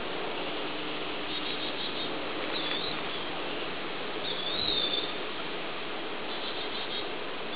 Carolina Chickadee